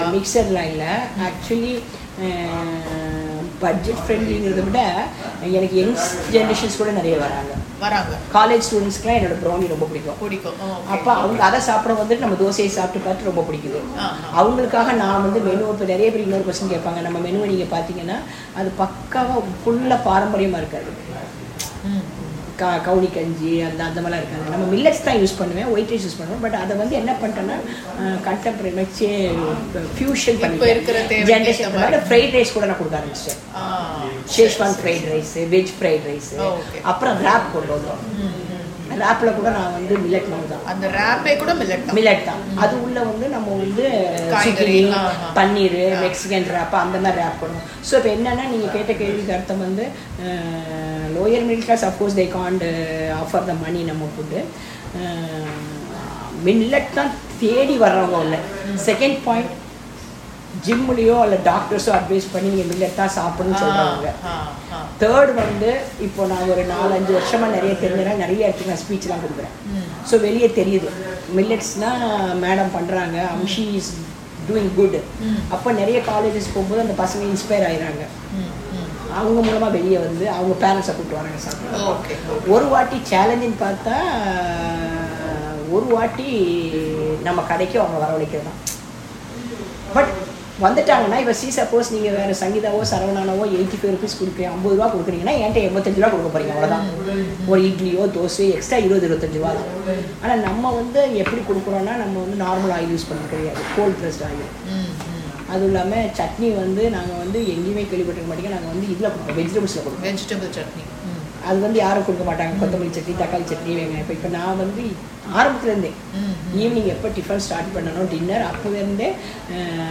நேர்காணல்கள்